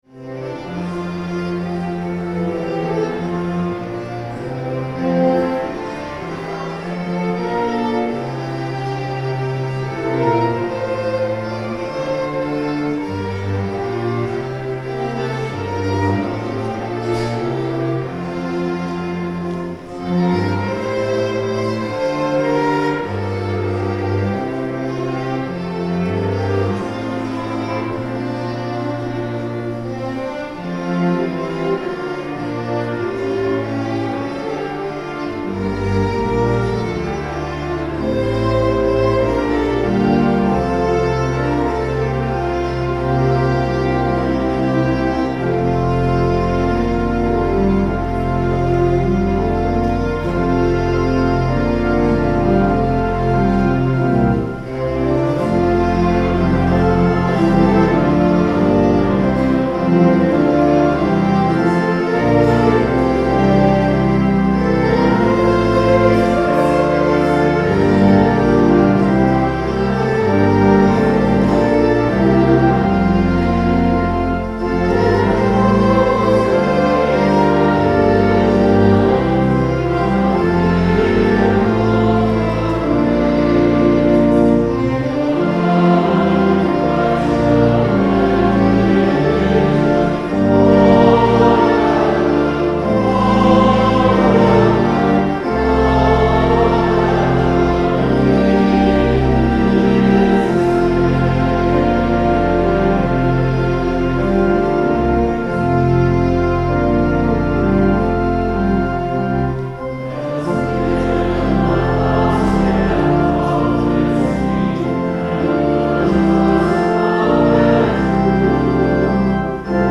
Music Featuring the Strings of St John's
Sermon
The Lord’s Prayer (sung)